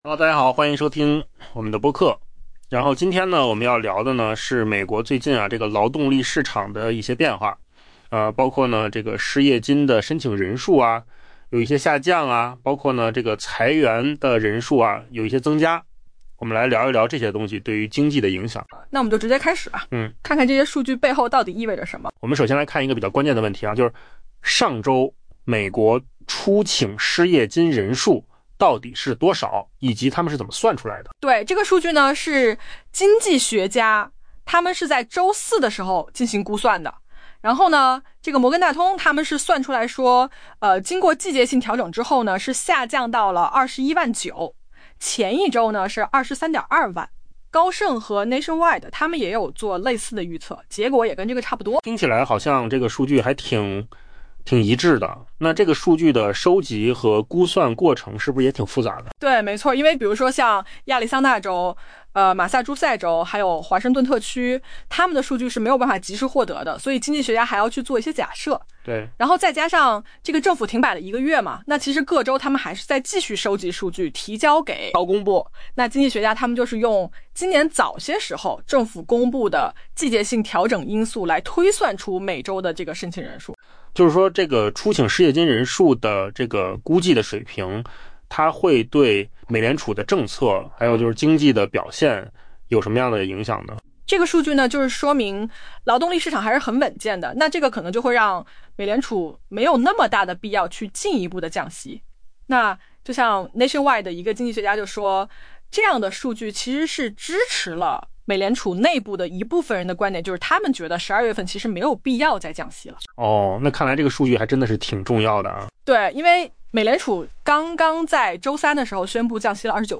AI 播客：换个方式听新闻 下载 mp3 音频由扣子空间生成 经济学家周四估计，上周美国初请失业金的人数有所下降，但就业市场仍存在裂痕，由于企业不愿增加招聘，那些被裁员的人获得新工作机会仍然稀缺。